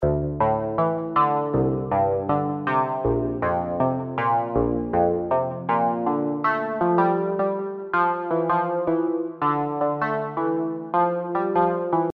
Ich habe einfach mal spontan in U-he's Hive 2 "guitar" eingegeben und mir einige Presets angehört - dieses fängt den Flair des von dir gesuchten Sounds meiner Meinung nach ganz gut ein: your_browser_is_not_able_to_play_this_audio Dieser Sound hier (von Tone2 Saurus) geht auch in die Richtung: your_browser_is_not_able_to_play_this_audio